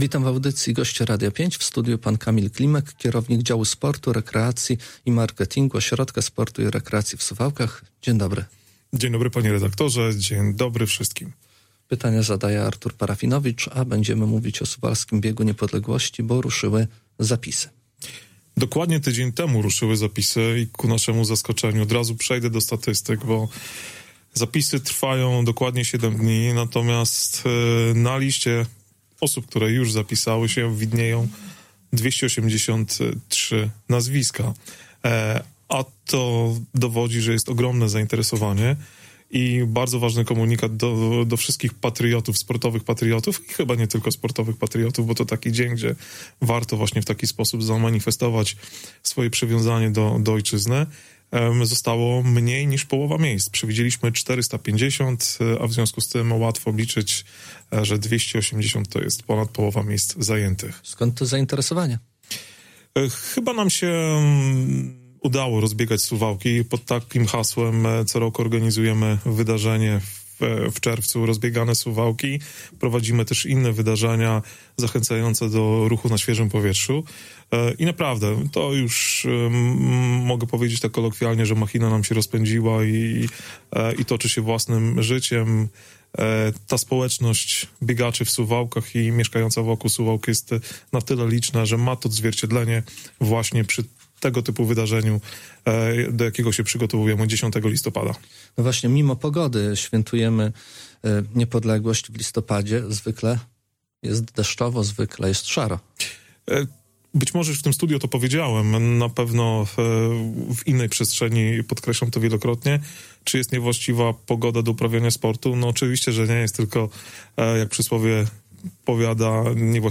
Cała rozmowa poniżej, a zapisy na stronie internetowej suwalskiego OSiRu. https